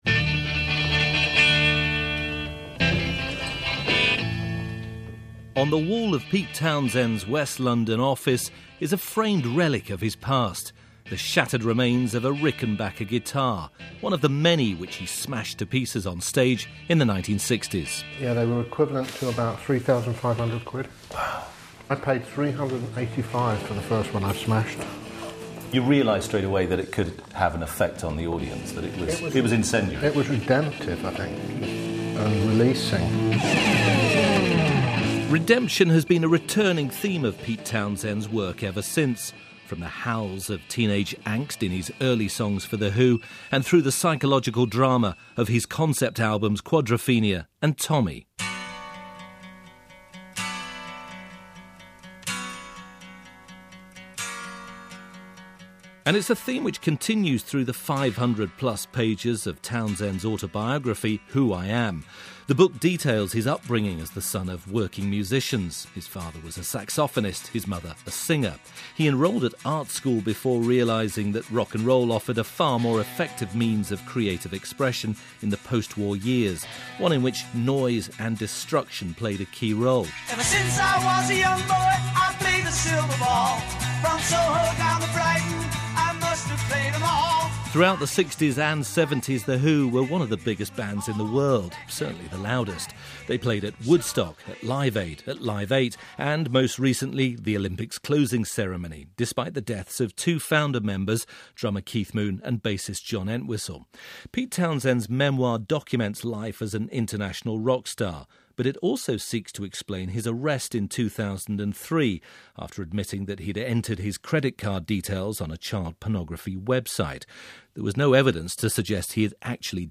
In case you may have missed this one, and certainly you would if you lived in the U.S., here is an interview done earlier this past week on the BBC Radio 4 program Front Row where Townshend discusses a number of subjects, current as well as past.